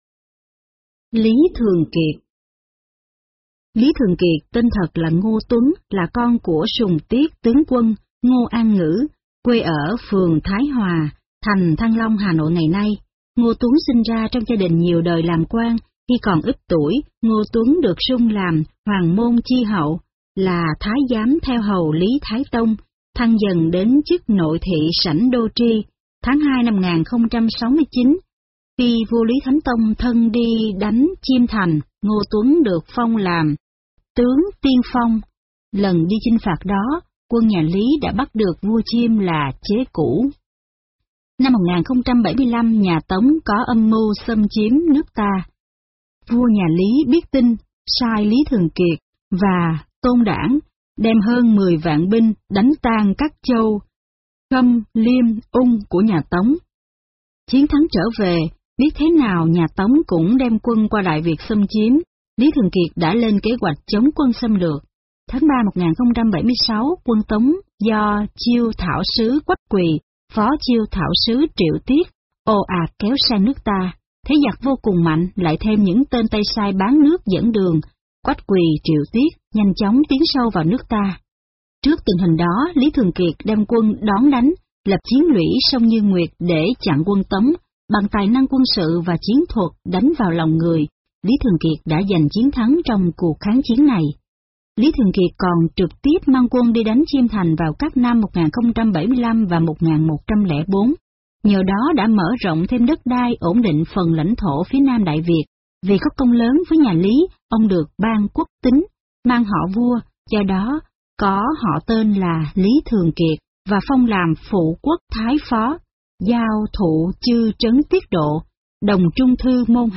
Tuyển tập sách nói Kể chuyện danh nhân Việt Nam khắc hoạ, phản ánh một cách chân thực, sinh động cuộc đời, sự nghiệp cũng như những chiến công hiển hách của các danh nhân lịch sử dân tộc.